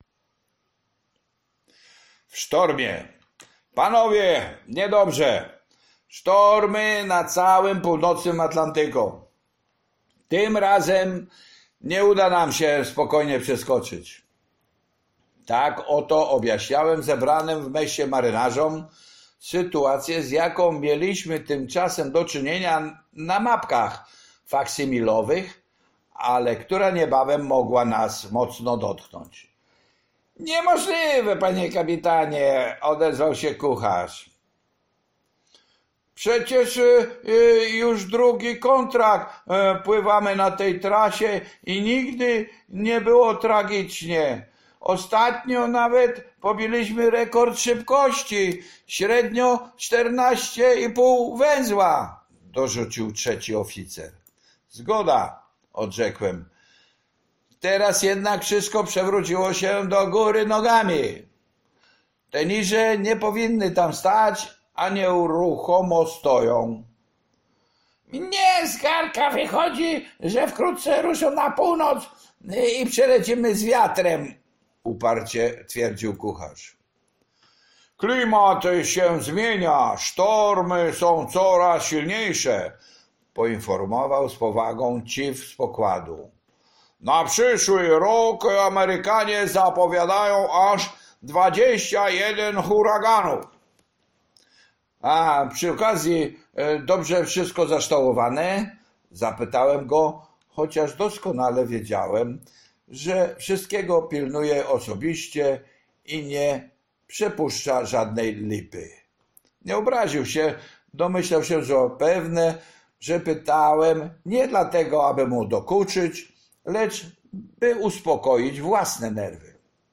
Życie marynarskie (audiobook). Rozdział 32 - W sztormie - Książnica Pomorska